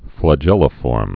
(flə-jĕlə-fôrm)